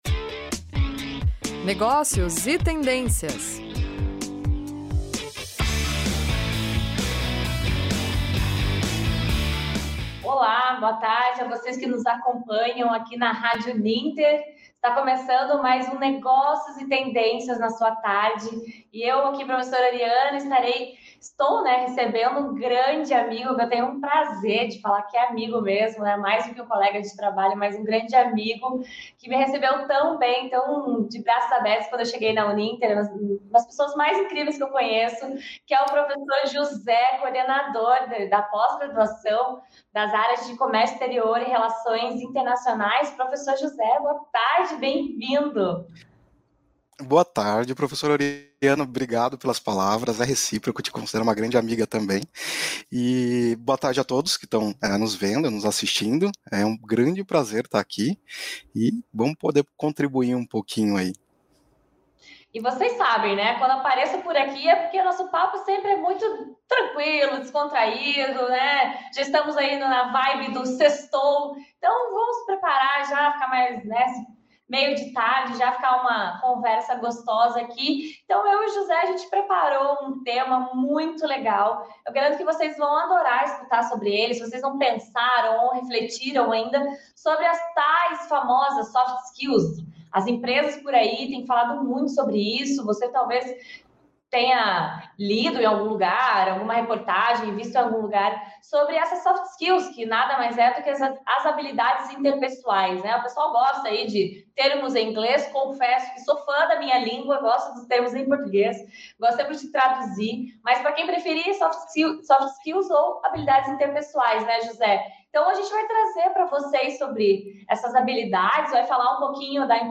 Este é o tema do Negócios e Tendências e o bate-papo